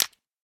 ignite.ogg